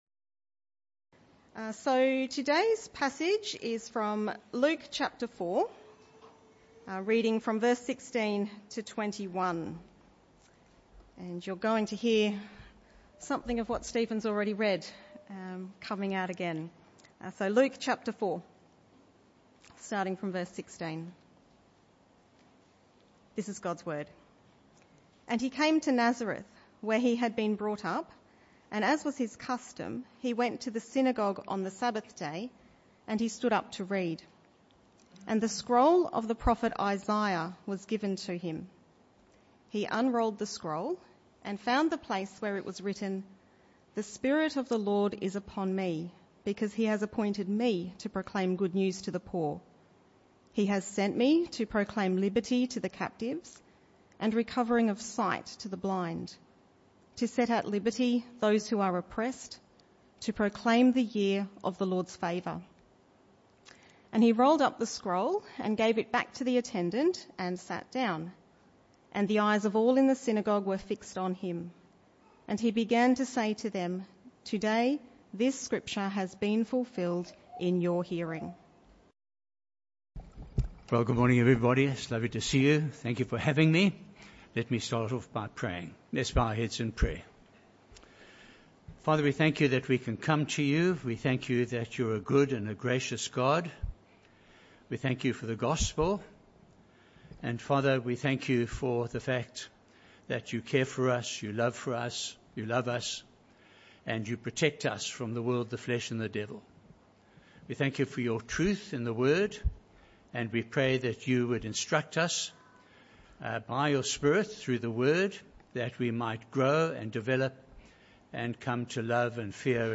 This talk was a one-off talk in the AM Service.